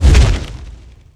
sphere_blowout.ogg